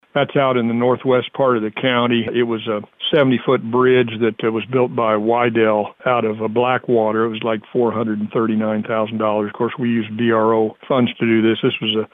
A new bridge on Livingston County Road LIV 519 was opened following an inspection by the Livingston County Commission and engineers.  Presiding Commissioner Ed Douglas says this was one of the BRO projects.